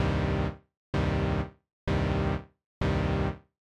Konkret: einen typischen Moll-Dreiklang aus C, Es und G. In der Pianorolle von Bitwig wählen wir dafür die Oktave 2, also die Töne C2, D#2 und G2.
Wir haben uns für einen reinen Sägezahn entschieden – die dafür nötige Mittelstellung des Shape-Reglers erzielst Du mit einem schnellen Doppelklick.
5 Suboszillator
Mit einem Suboszillator machen wir den Klang fetter und basslastiger. Der Suboszillator des Polysynth schwingt in einer um eine Oktave tiefer gestimmten Rechteckswelle.
In unserem Fall wählen wir einen Sub-Anteil von 33% und belassen dessen Pulsweite in der Mittelstellung.
subtraktive_synthese_bitwig_tutorial__05_suboszillator.mp3